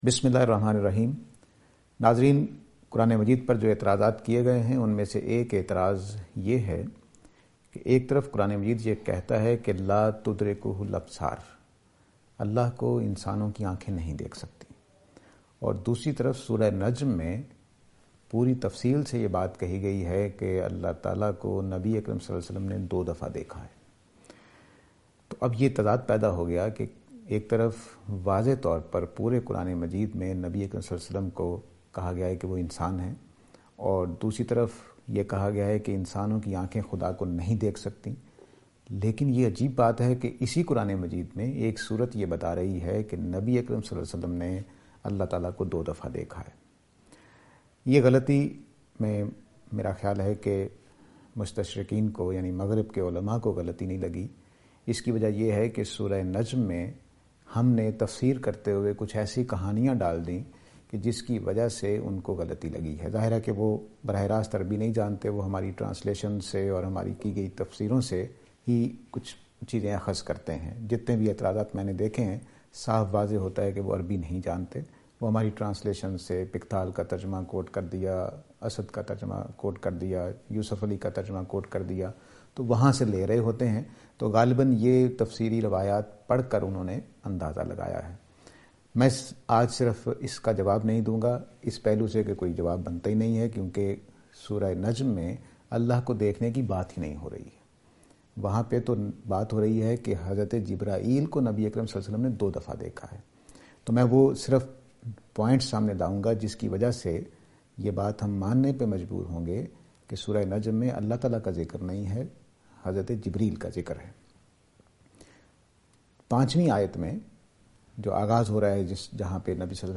This lecture will present and answer to the allegation "We cannot watch Allah but?".